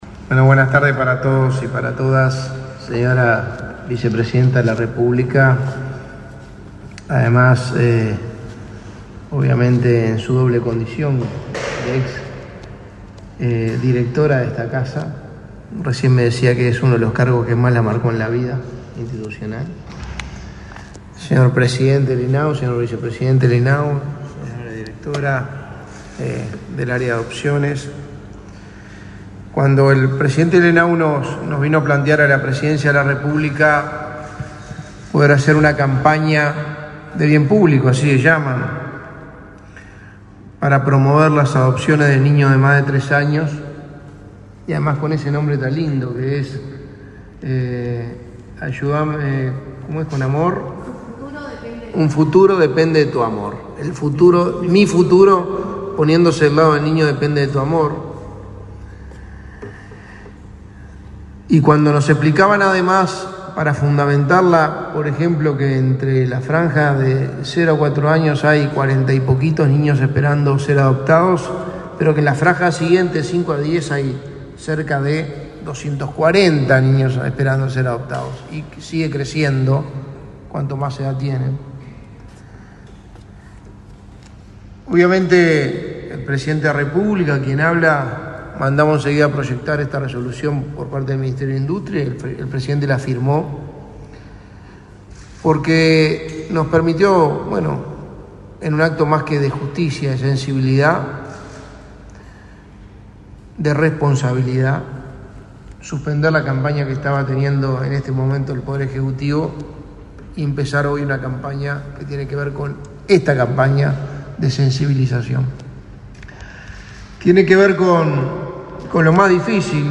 Palabras del secretario de la Presidencia, Álvaro Delgado
Con la presencia del secretario de la Presidencia, Álvaro Delgado, se realizó, este 18 de octubre, el lanzamiento de la campaña de adopciones “Su